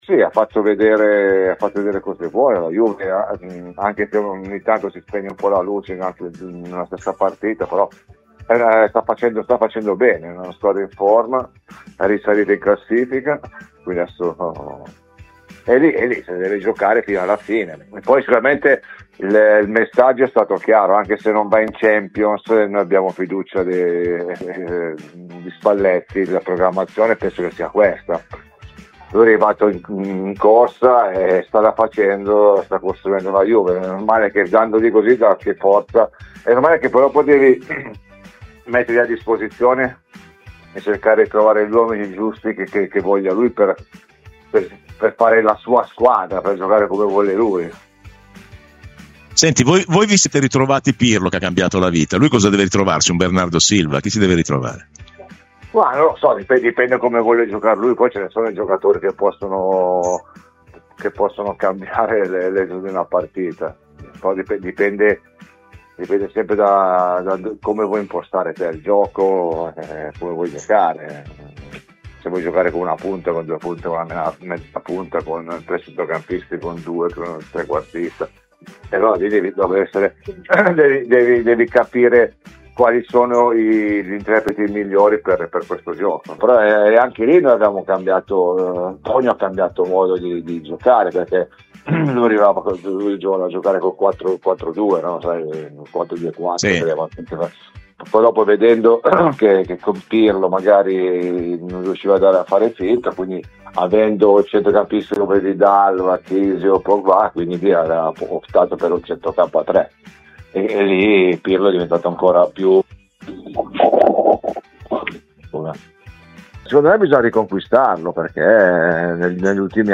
Massimo Carrera è intervenuto a Radio Bianconera, nel corso di Rassegna Stramba.